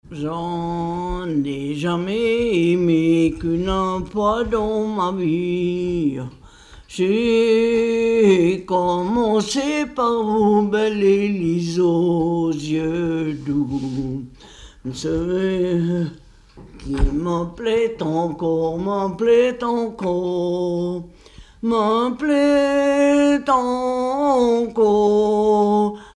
Localisation Mieussy
Catégorie Pièce musicale inédite